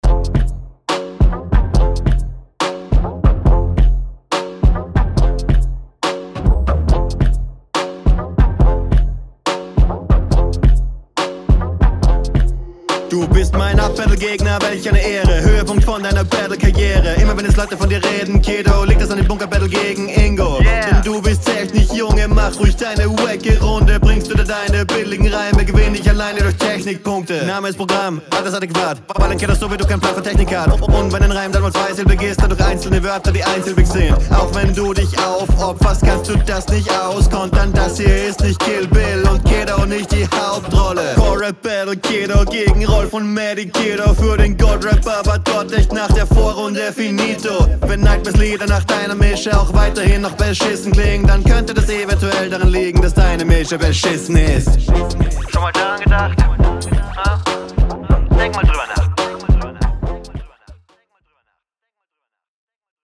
Flowpatterns machen Spaß, die Aussprache lässt aber noch zu wünschen übrig.
Leider hast du hier den beat etwas suboptimal gemischt, sodass deine Stimme untergeht an manchen …